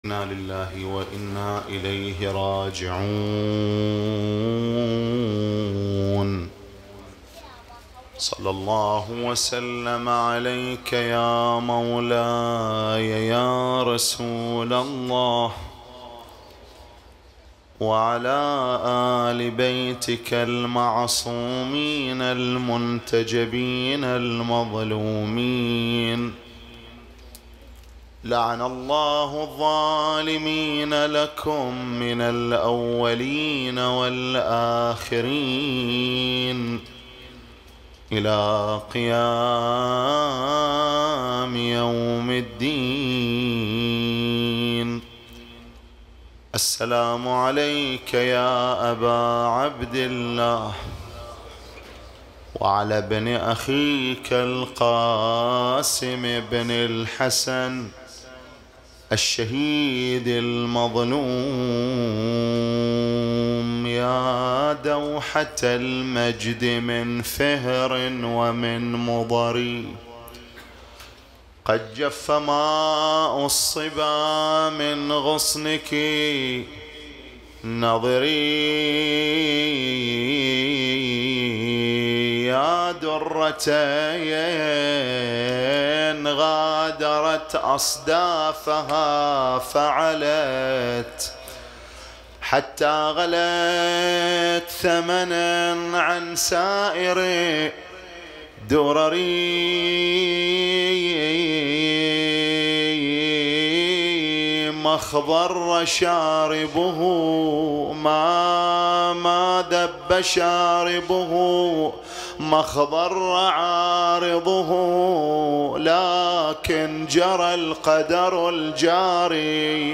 الليلة الثامنة من محرم 1447هـ || حسينية السيد الخوئي بسنابس